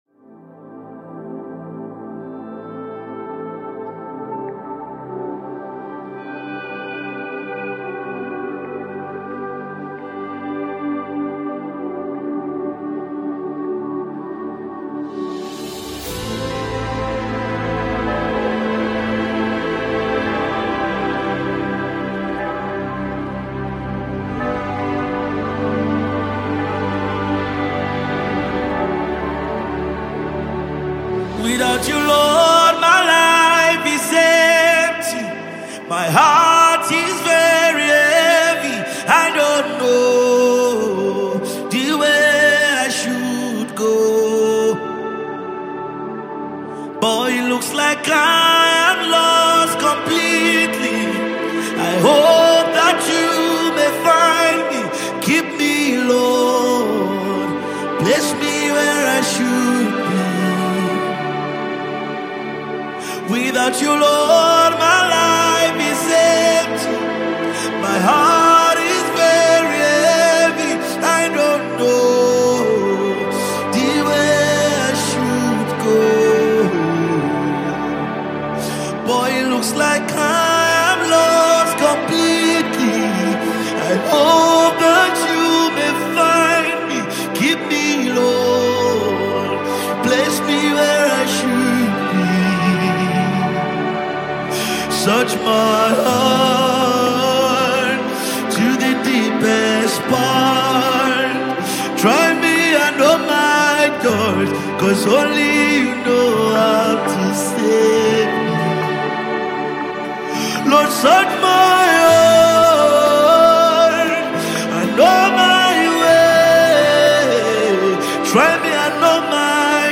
Nigerian gospel singer
Gospel